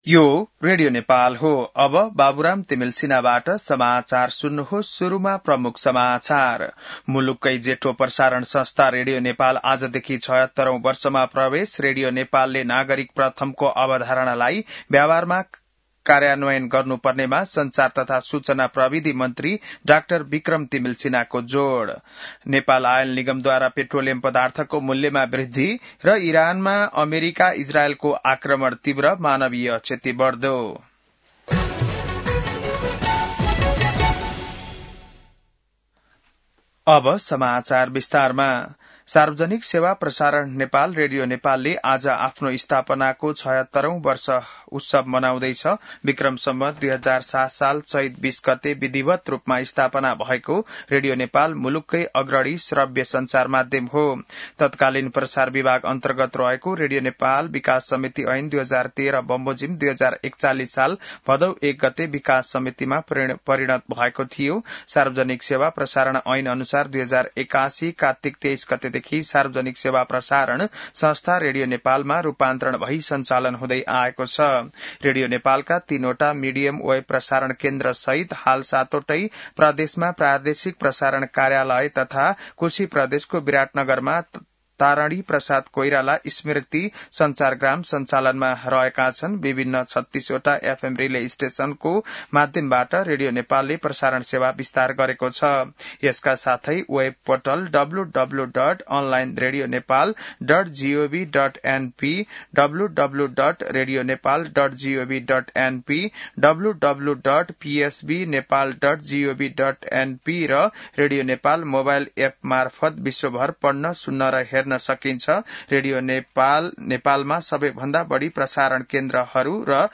बिहान ९ बजेको नेपाली समाचार : २० चैत , २०८२